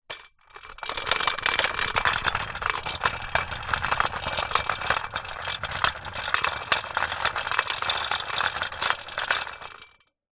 Звуки самоката
Детский самокат издает звук при езде